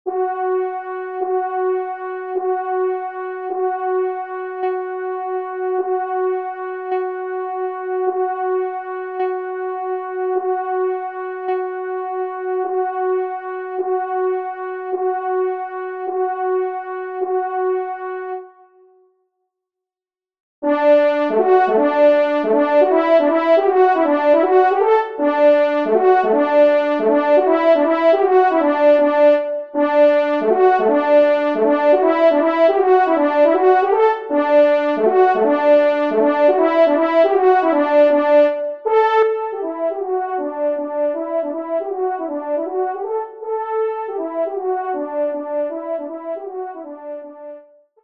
Danses polonaises
1e Trompe